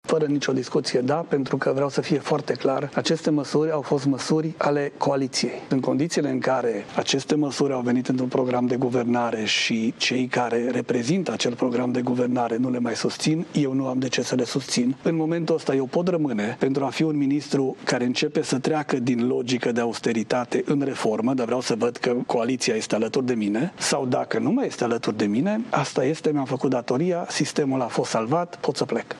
El a afirmat, într-un interviu pentru Digi 24, că, deşi nu este obligat, dacă moţiunea va fi adoptată, îşi va da demisia: